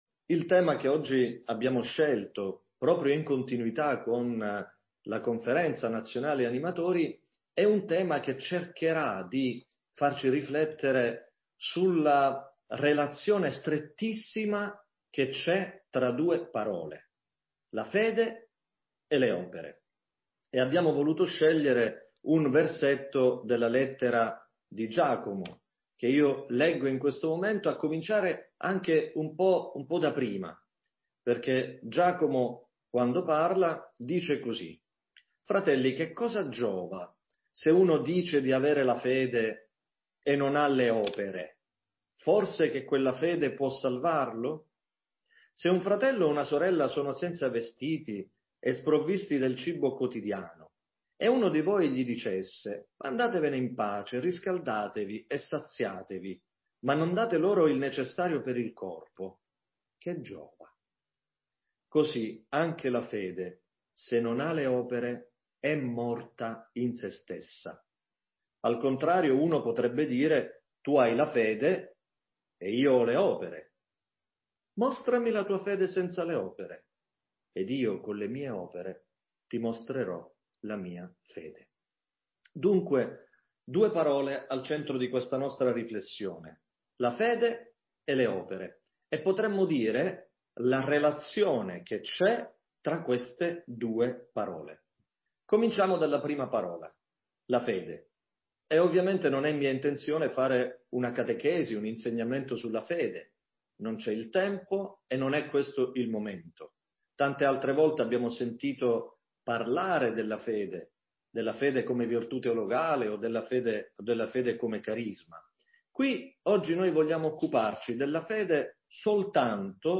catechesi-conferenza_animatori.mp3